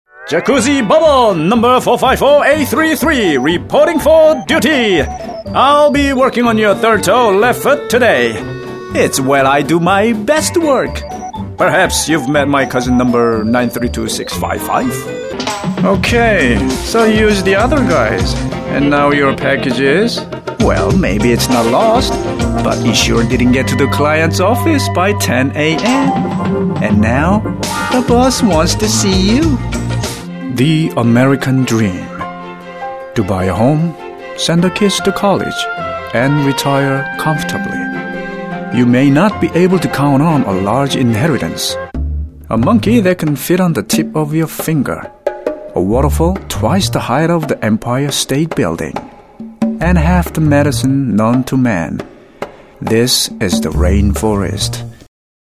Calm, audio book, authoritive, funny, character voice
Sprechprobe: eLearning (Muttersprache):